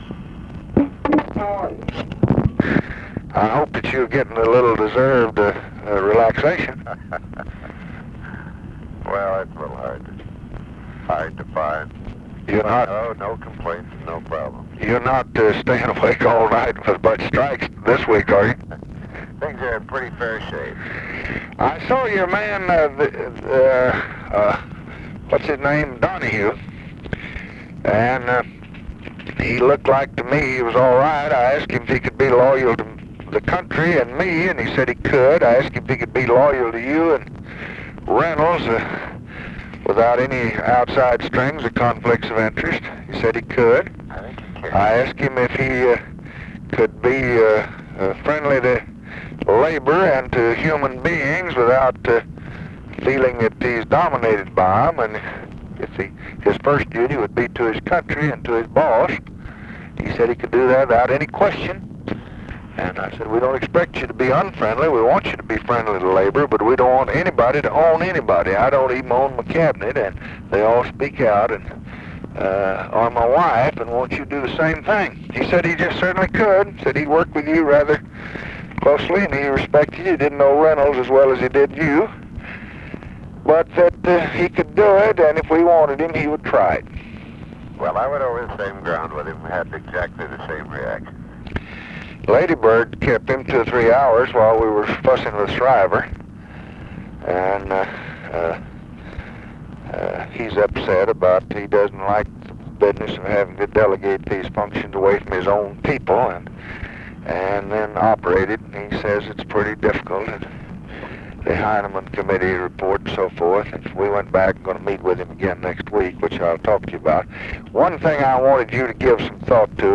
Conversation with WILLARD WIRTZ, December 30, 1966
Secret White House Tapes